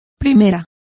Complete with pronunciation of the translation of firsts.